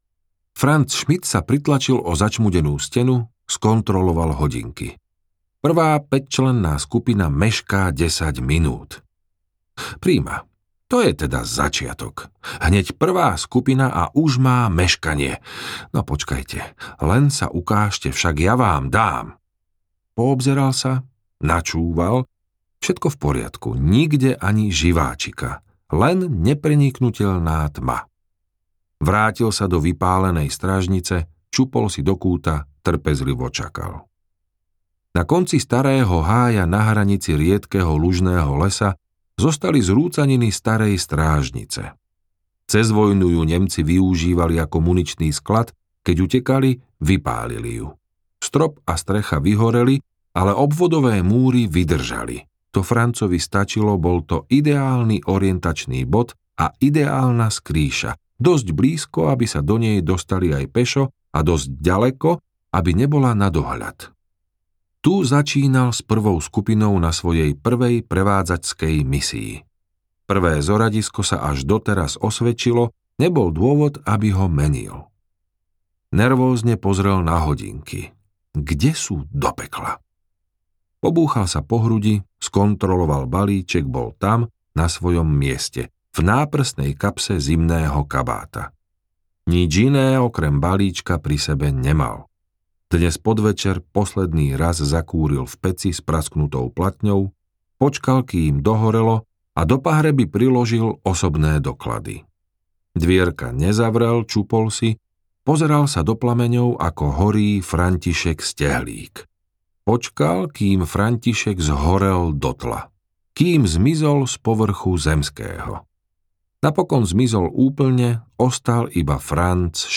Reminiscencie audiokniha
Ukázka z knihy